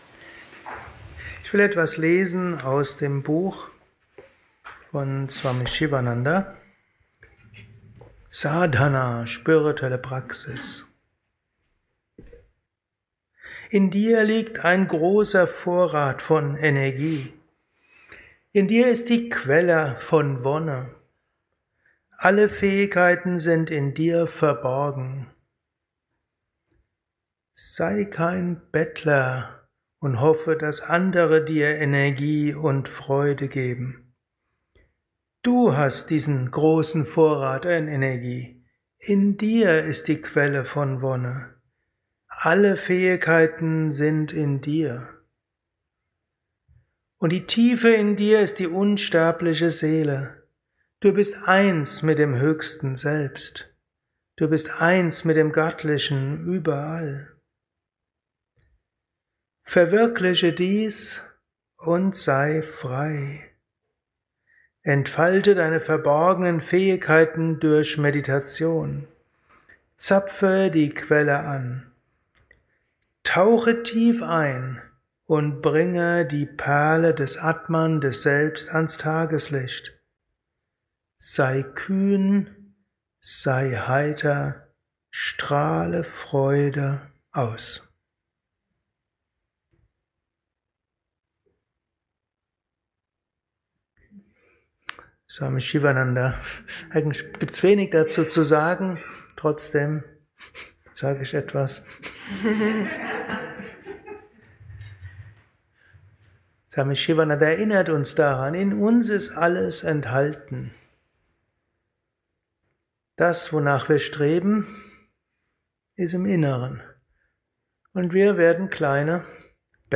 Aufnahme während eines Satsangs gehalten nach einer Meditation im